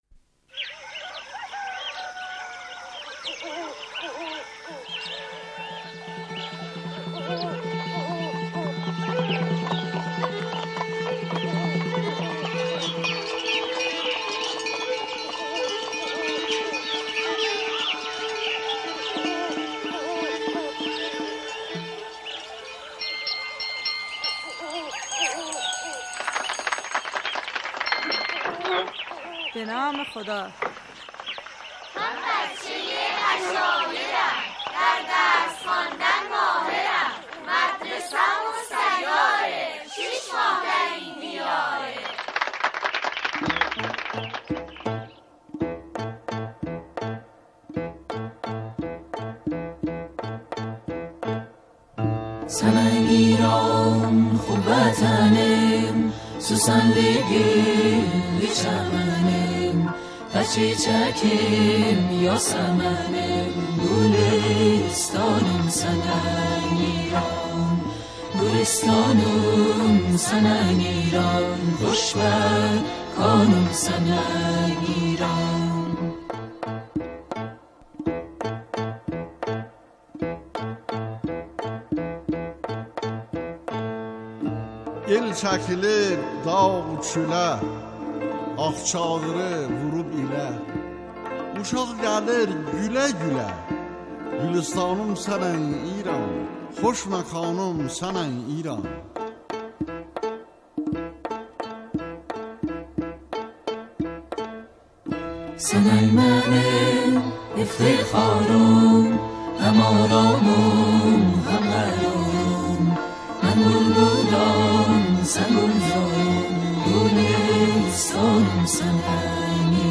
با گویش آذری